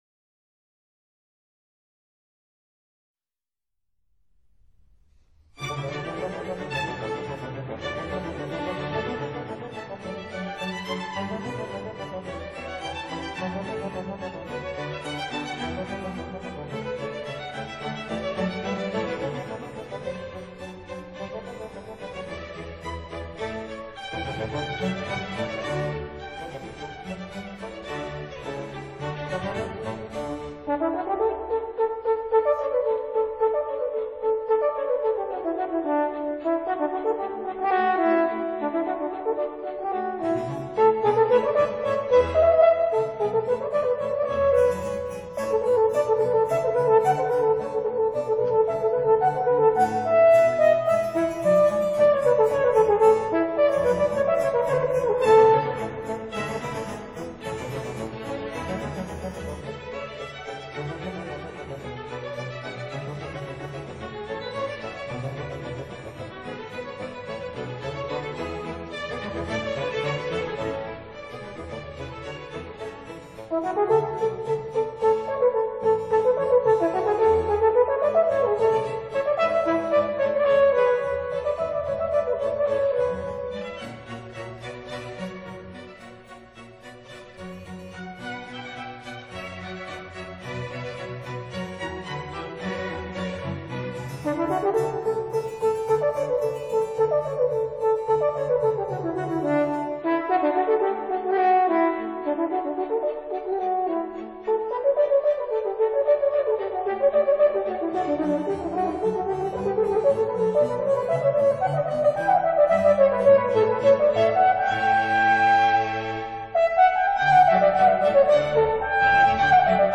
西洋樂器介紹：迷人的法國號（圓號）